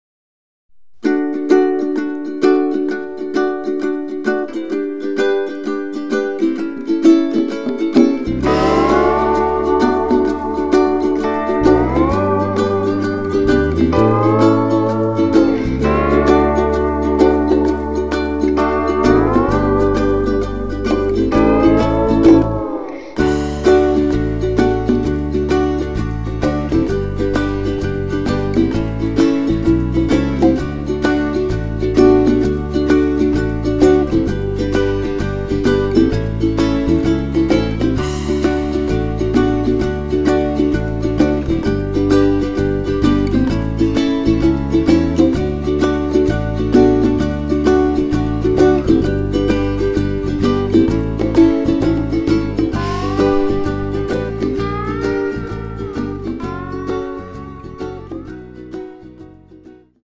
[공식 음원 MR]
키 D 가수